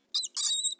Звук с писком песчанки